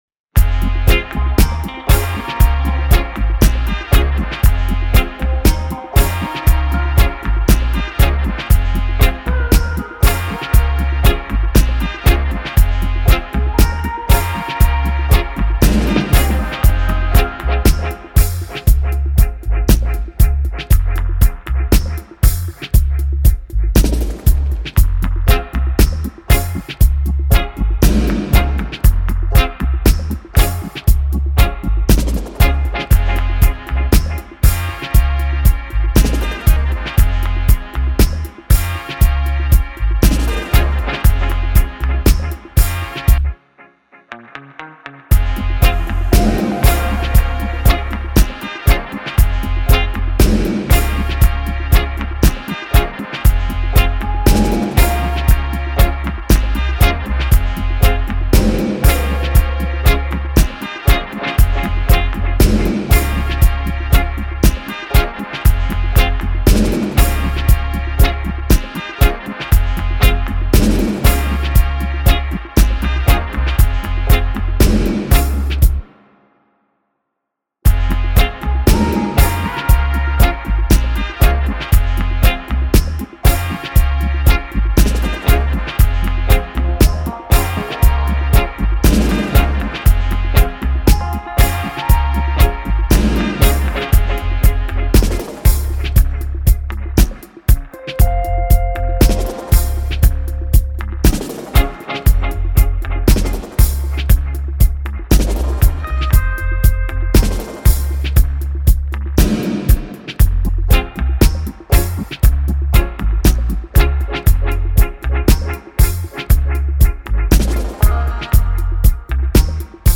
Official Instrumentals